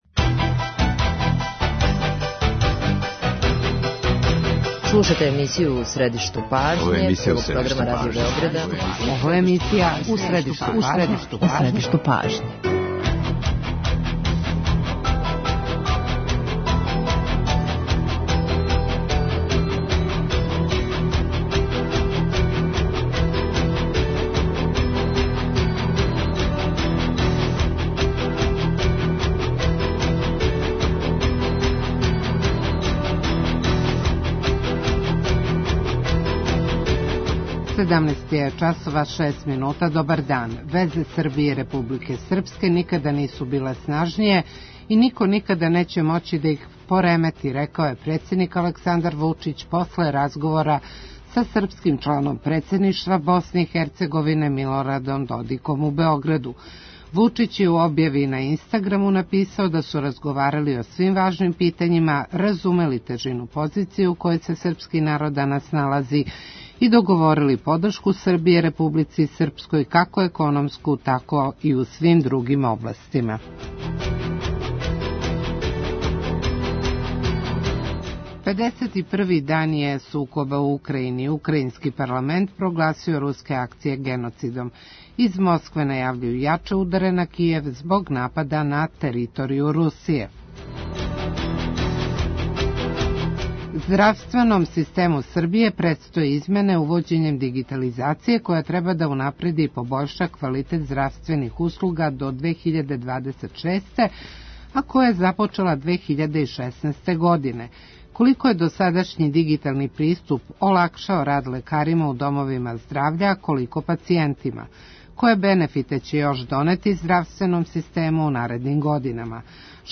Гост Првог Програма Радио Београда је државни секретар Министарства здравља, доц. др Предраг Саздановић.